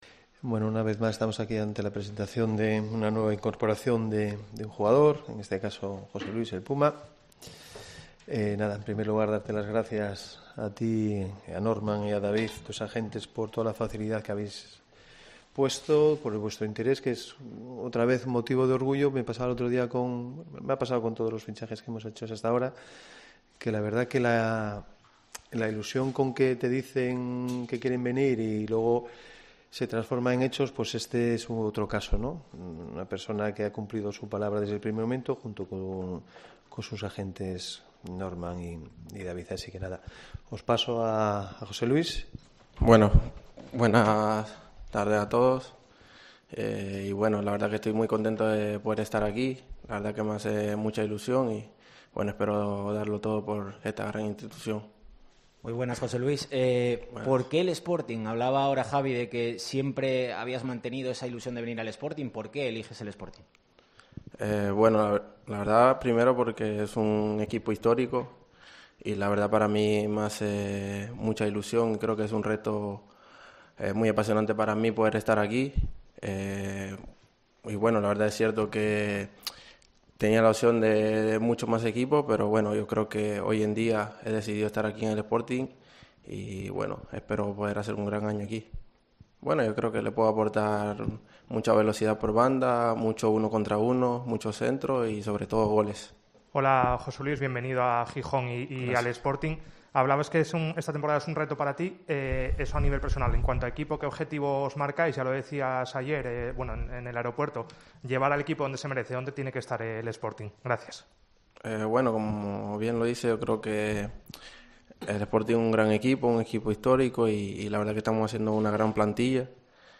AUDIO: Presentación del Puma Rodríguez como nuevo jugador del Sporting.